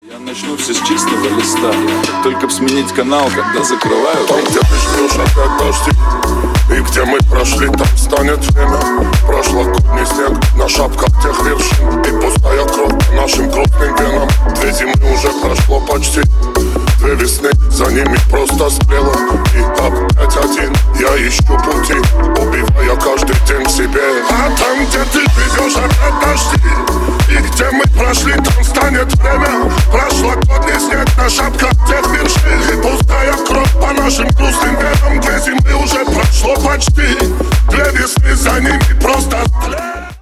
Ремикс
клубные # громкие